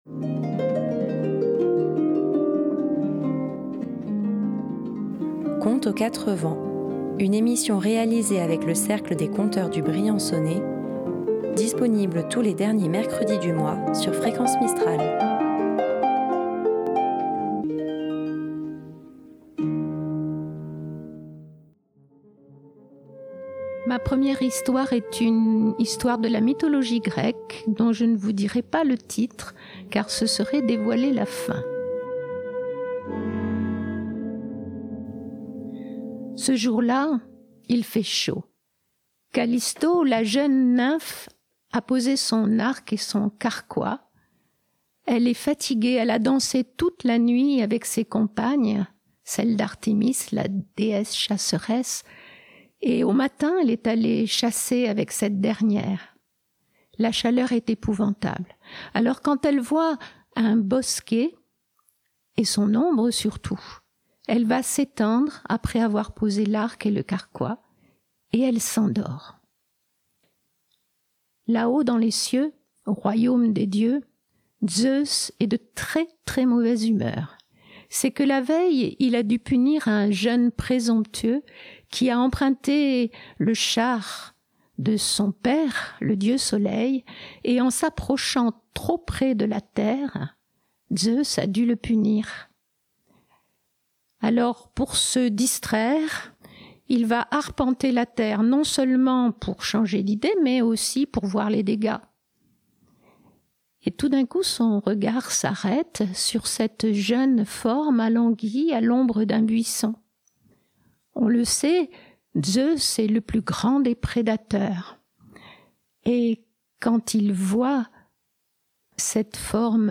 Tous les derniers mercredi du mois, à 18h11, retrouvez le Cercle des conteurs du Briançonnais pour une balade rêveuse.